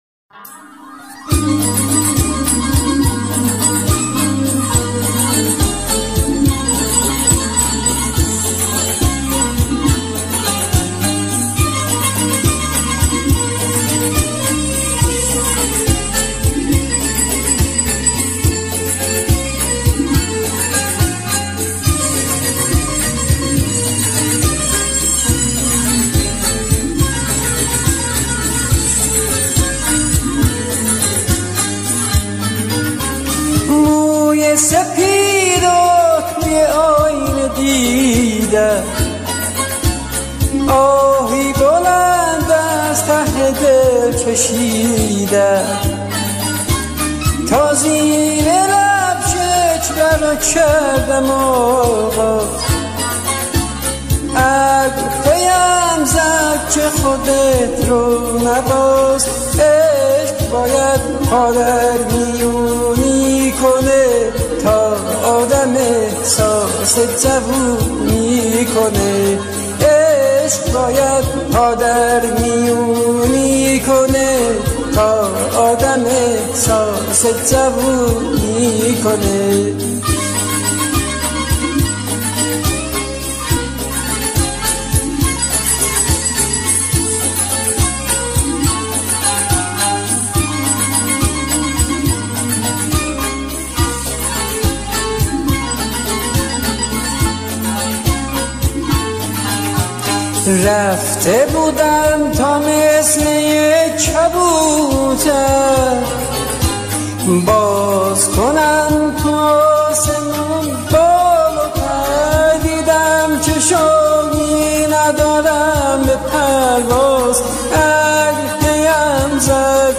در دستگاه شور به اجرا درآمده است
موسیقی سنتی ایرانی